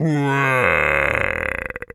hippo_groan_05.wav